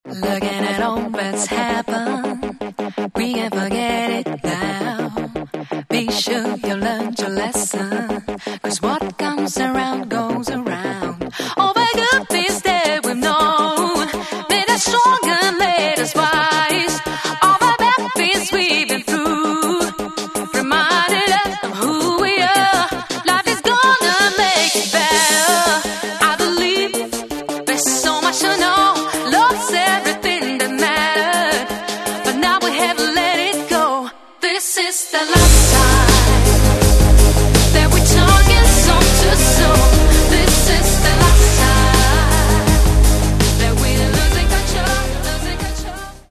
Каталог -> Поп (Легкая) -> Клубная